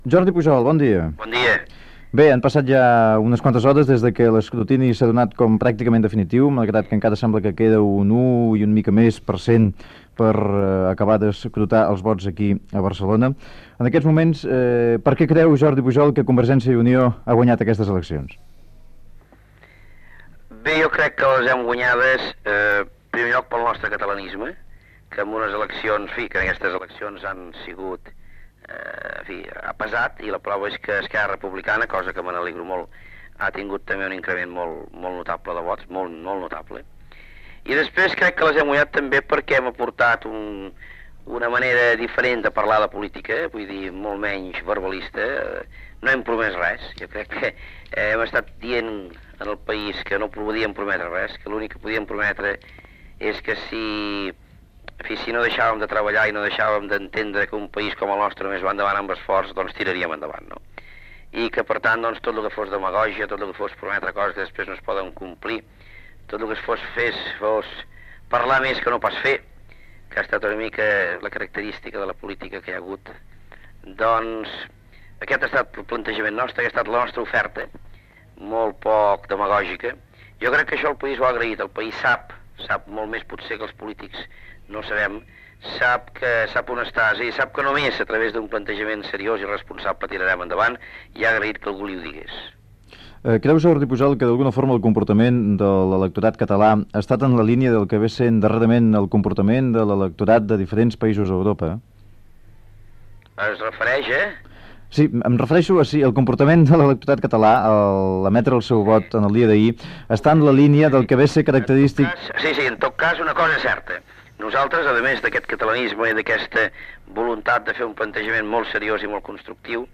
Entrevista a Jordi Pujol, de Convergència i Unió, per valorar el resultat i la victòria a les eleccions al Parlament de Catalunya, celebrades el dia abans
Informatiu